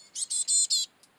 シジュウカラの雌とヒナ・幼鳥の鳴き声
メスの営巣時の鳴き声/DW/(図-41)と巣立ち後のヒナの鳴き声(図-42)の「begging call（餌乞い声）」はよく似ている。